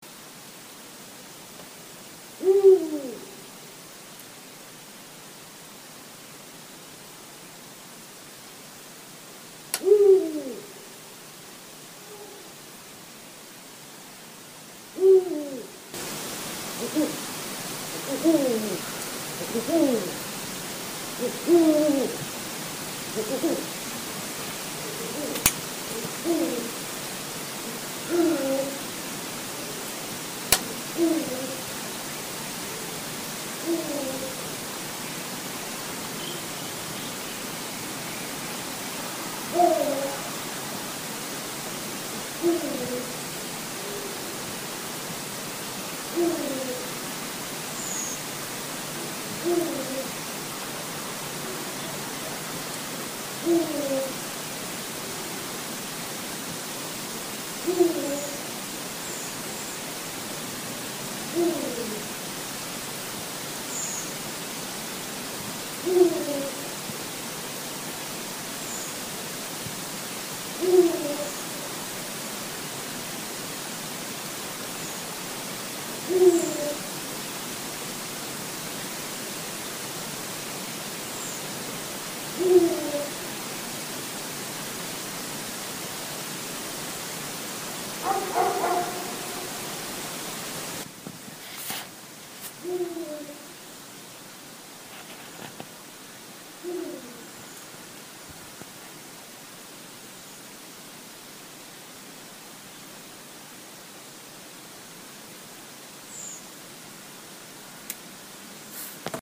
Owl #1